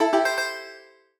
trade_bot/banjo_e1a1e1g12c2.ogg at 55f020e6001cac632a149dd573fd5d36c2b8a22b